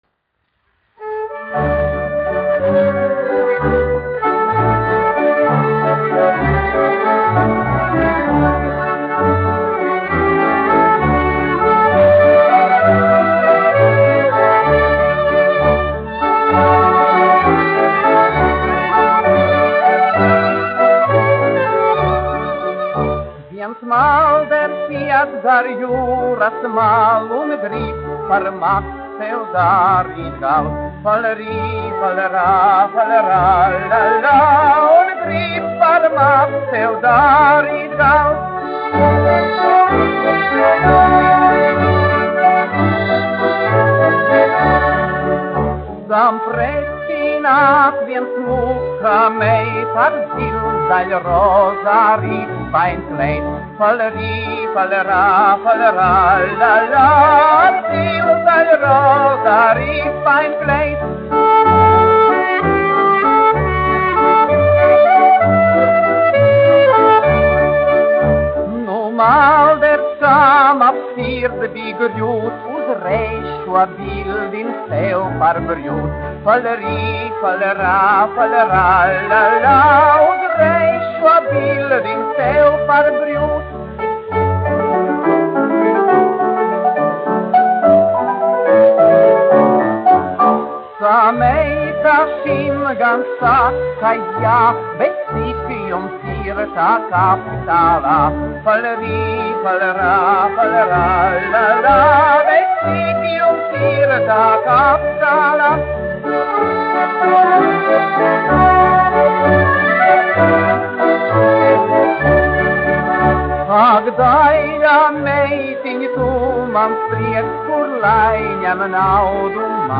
1 skpl. : analogs, 78 apgr/min, mono ; 25 cm
Populārā mūzika
Skaņuplate